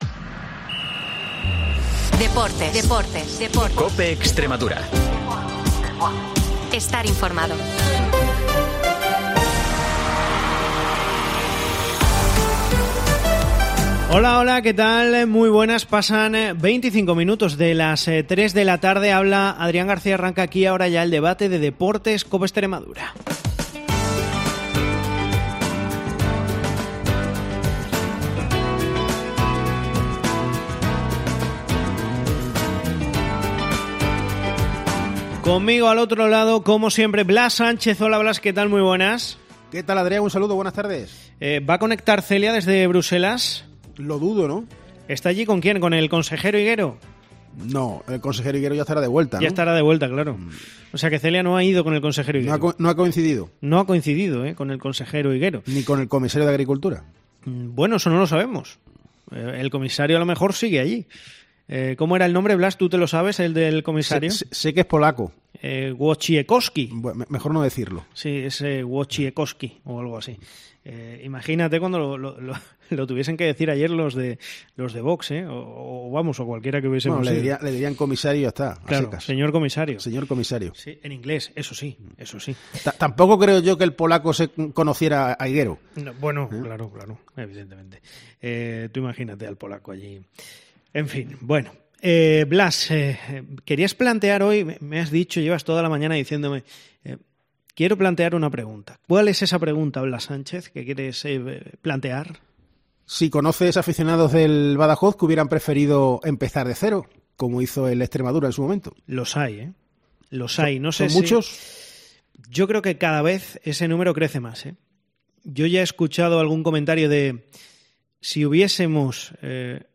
AUDIO: El debate de deportes de COPE Extremadura